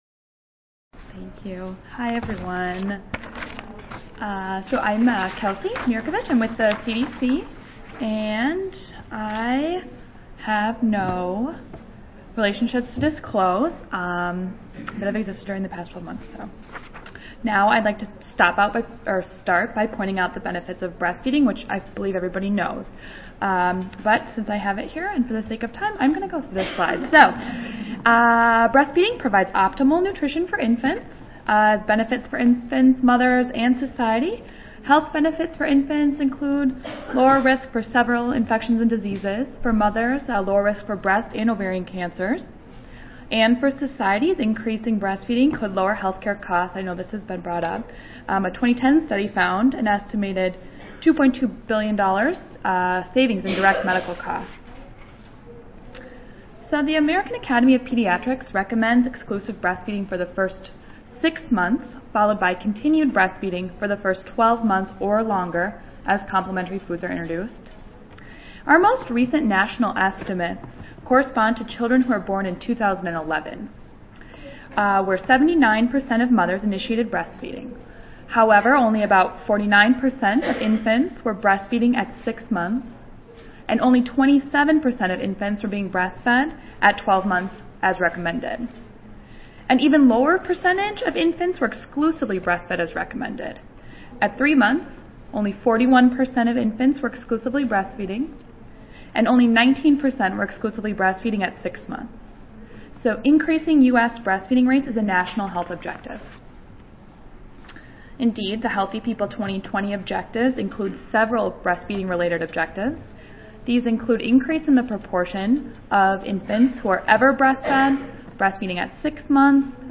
142nd APHA Annual Meeting and Exposition (November 15 - November 19, 2014): Breastfeeding Forum Oral Session 2-Employment and Breastfeeding